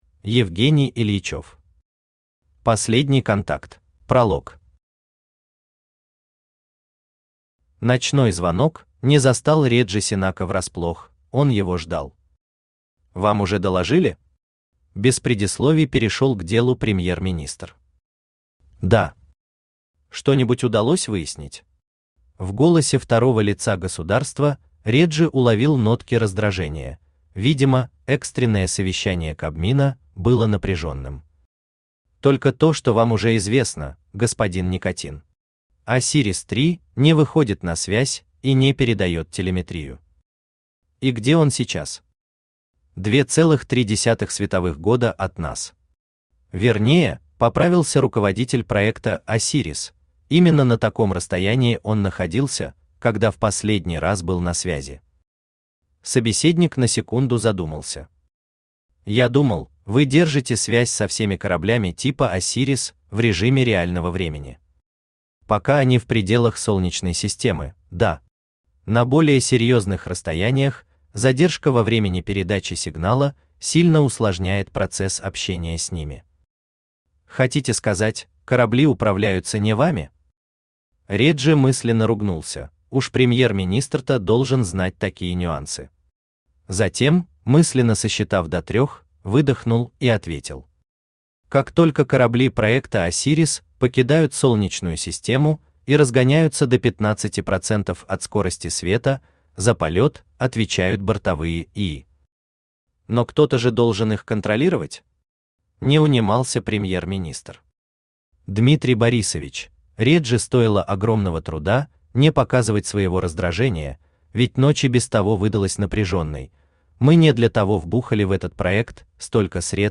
Аудиокнига Последний контакт | Библиотека аудиокниг
Aудиокнига Последний контакт Автор Евгений Юрьевич Ильичев Читает аудиокнигу Авточтец ЛитРес.